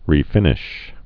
(rē-fĭnĭsh)